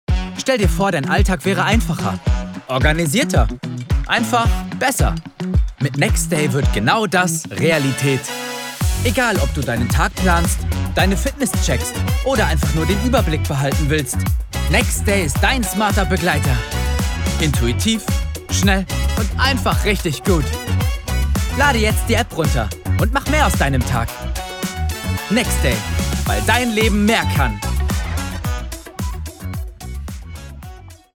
Klare Commercial-Voice mit positivem Drive. Frisch, motivierend.